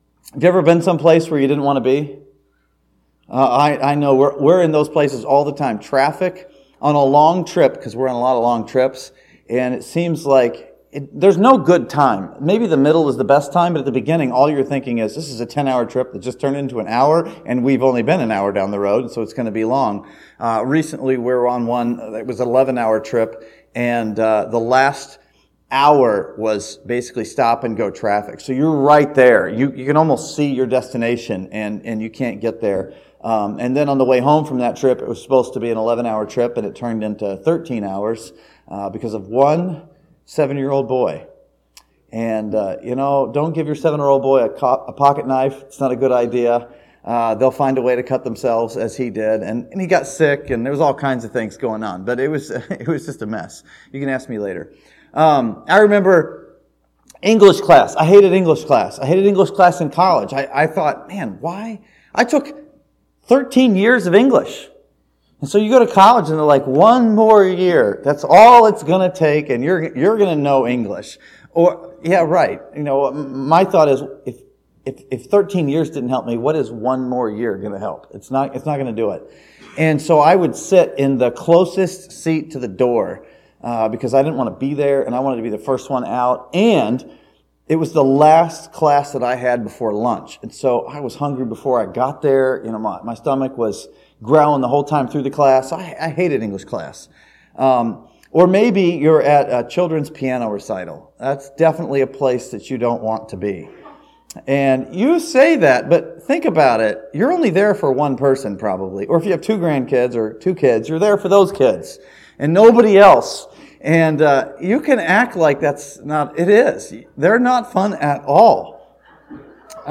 This sermon from Exodus chapter 14 looks at the place where you are as the place God wants you to be.